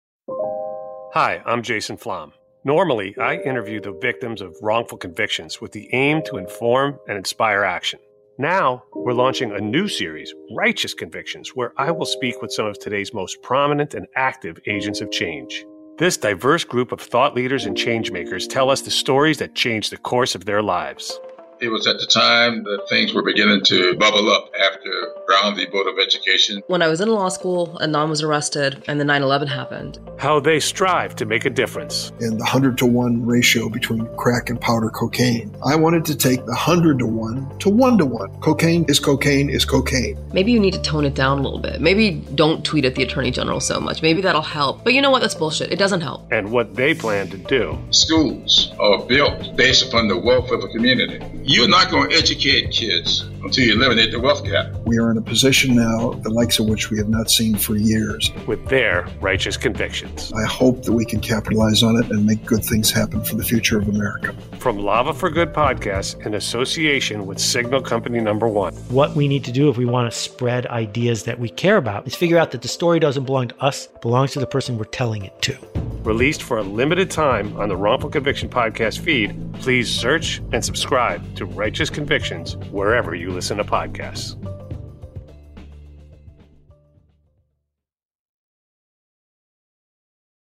Wrongful Conviction Podcasts' Jason Flom speaks with some of today's most prominent and active thought leaders and change makers in a new interview series from Lava for Good Podcasts in association with Signal Co No 1.